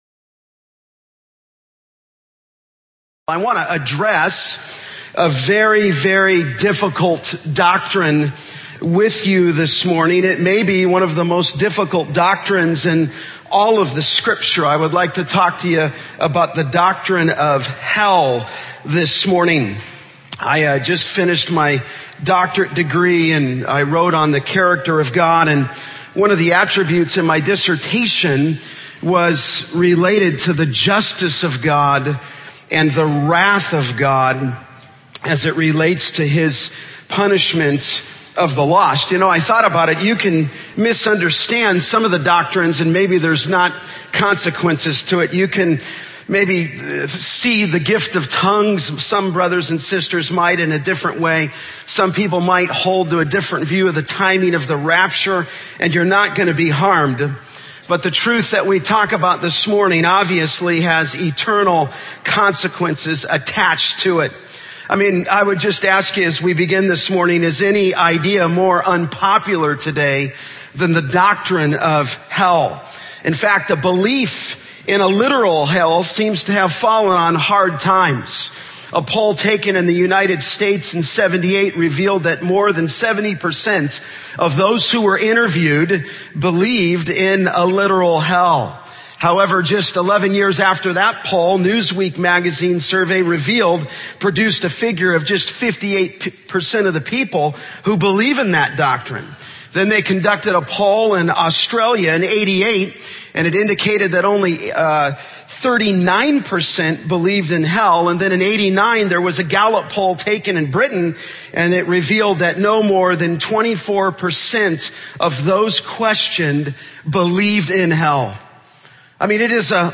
In this sermon, the preacher focuses on the story of the rich man and Lazarus from Luke 16:19-31. He believes that this account is not a parable but an actual description of the afterlife. The preacher emphasizes the importance of sharing the gospel and the hope found in Jesus Christ.